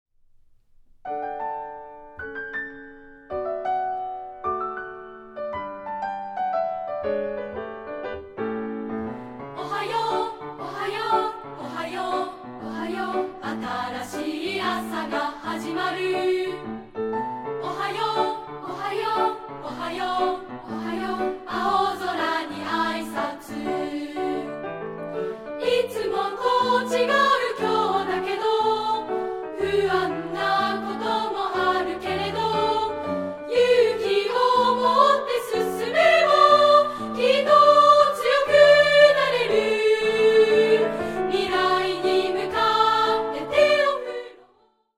部分2部合唱／伴奏：ピアノ